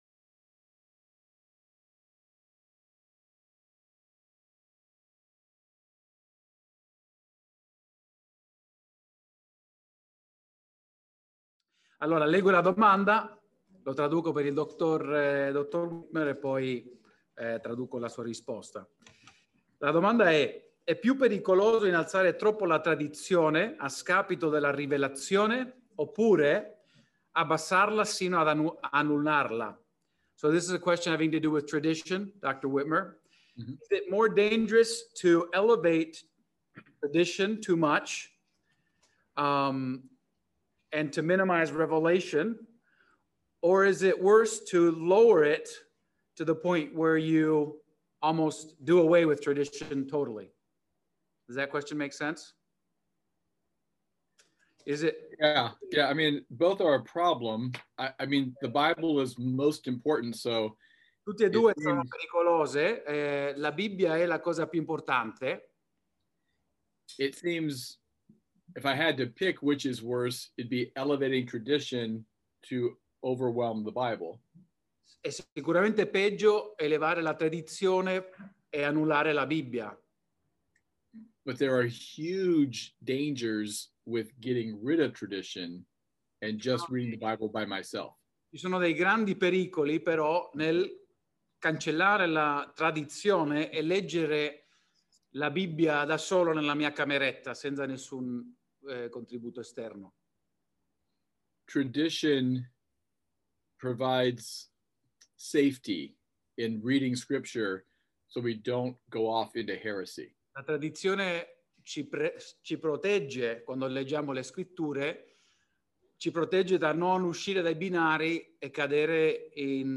S03-Domande-e-Risposte.mp3